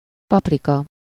Ääntäminen
Synonyymit piment doux piment fort piment Ääntäminen France: IPA: [pwa.vʁɔ̃] Tuntematon aksentti: IPA: pwavrɔ̃ Haettu sana löytyi näillä lähdekielillä: ranska Käännös Ääninäyte Substantiivit 1. paprika Suku: m .